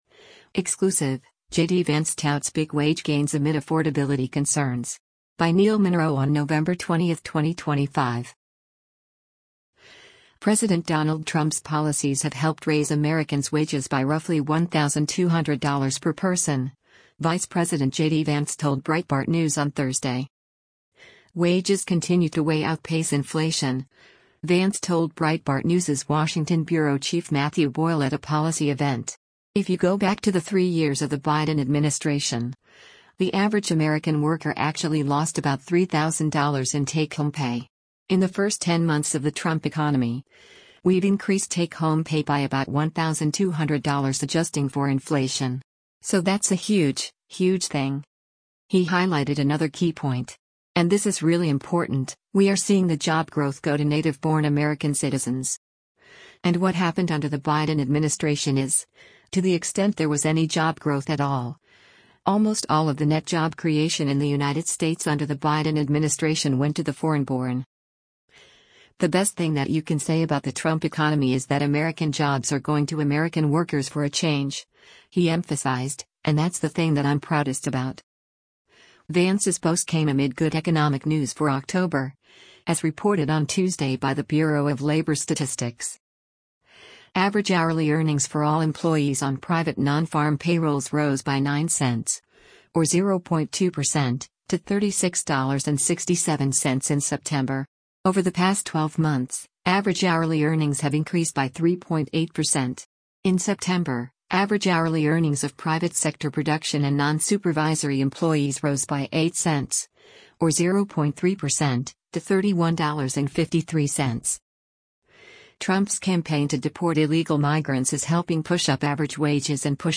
WASHINGTON, DC - NOVEMBER 20: U.S. Vice President JD Vance participates in a fireside chat